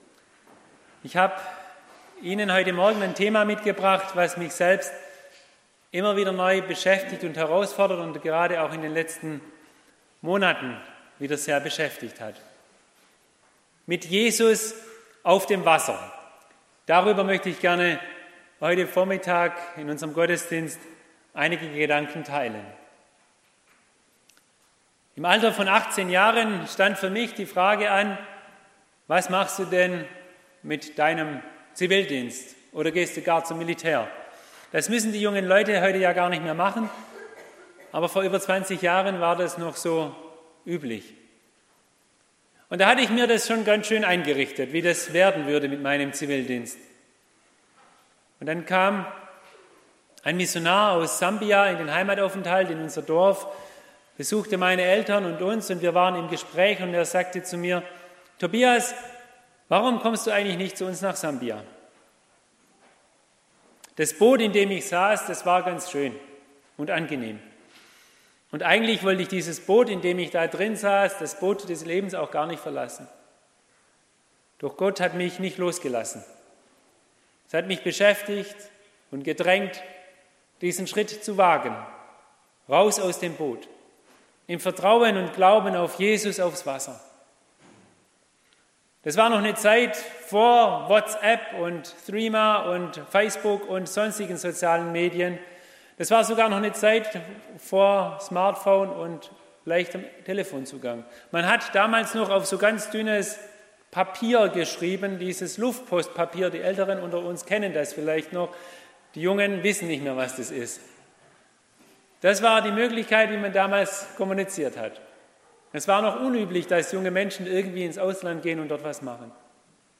Predigt
im Online-Gottesdienst am 21. Sonntag nach Trinitatis